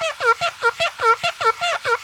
cartoon_squeaky_cleaning_loop_06.wav